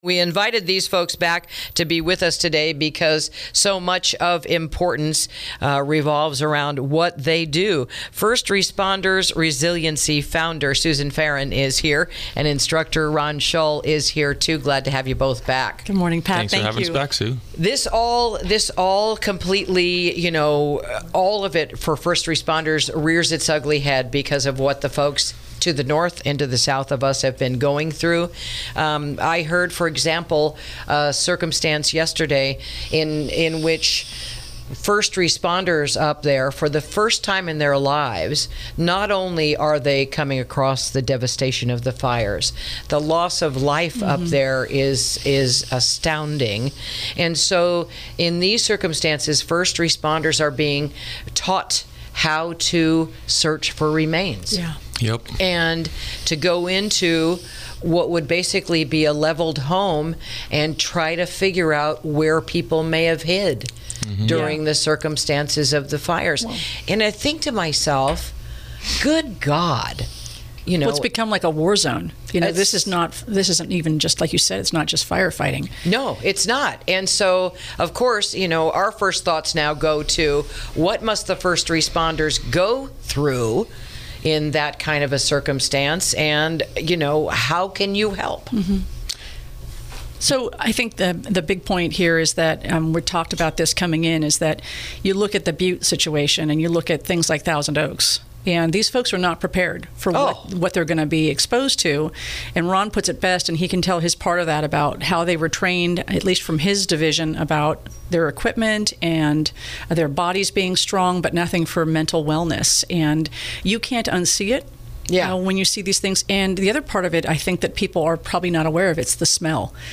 INTERVIEW: First Responder Resiliency's Fundraiser to Help Those Fighting the California Wildfires | KSRO 103.5FM 96.9FM & 1350AM